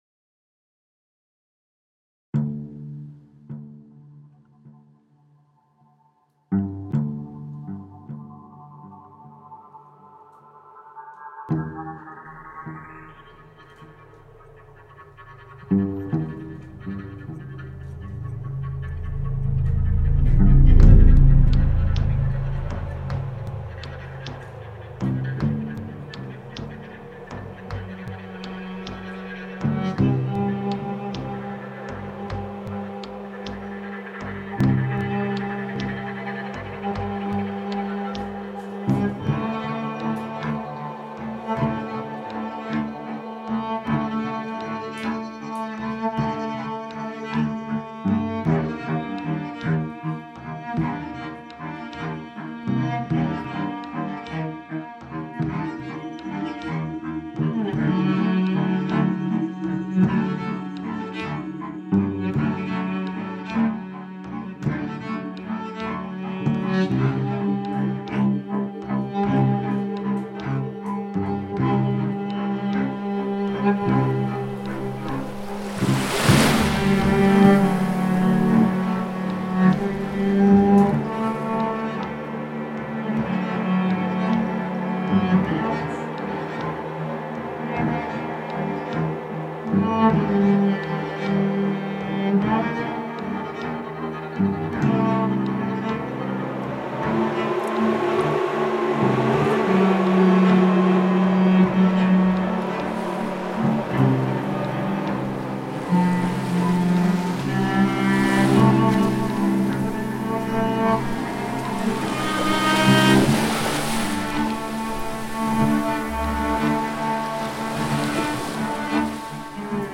2015 - musiques de scènes